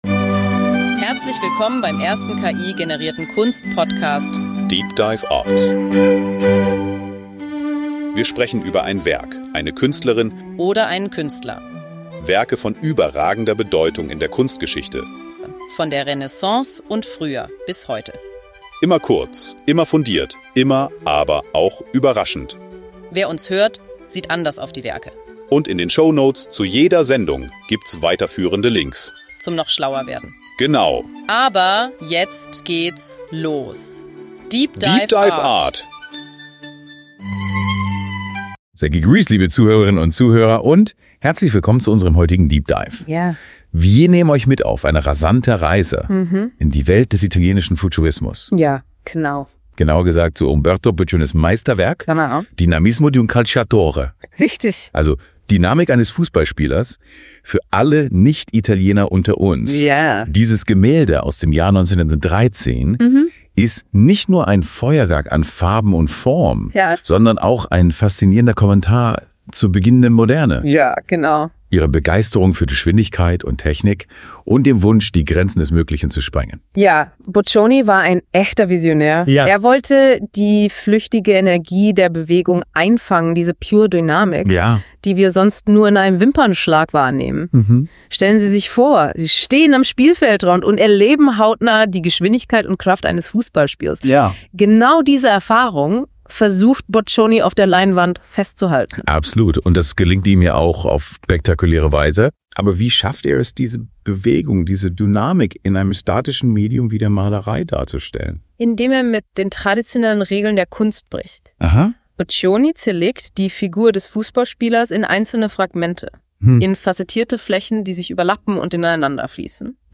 Zudem wird die Technik des simultanen Sehens erklärt und die Aktualität von Boccioni's Themen in der heutigen Zeit reflektiert DEEP DIVE ART ist der erste voll-ki-generierte Kunst-Podcast.
Die beiden Hosts, die Musik, das Episodenfoto, alles.